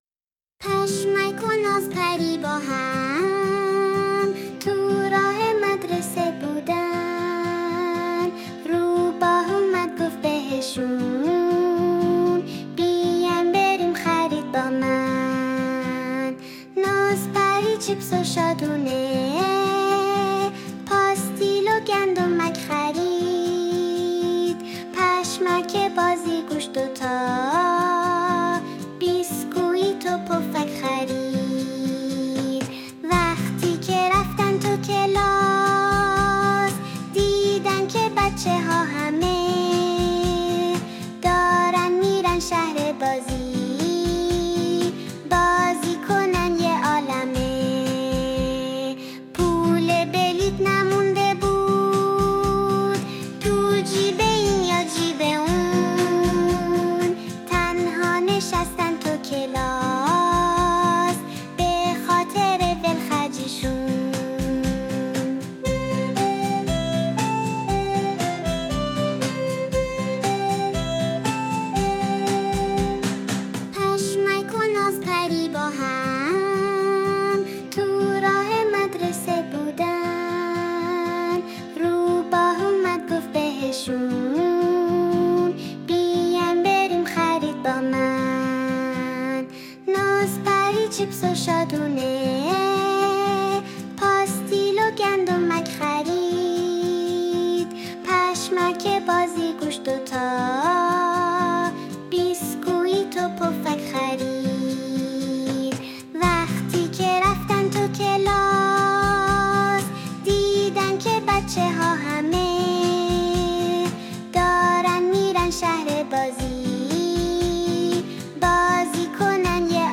فعالیت 4: شعر